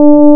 Wavefolds and Shape Bank Collection